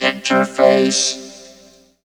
68.3 VOCOD.wav